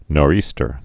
(nôr-ēstər)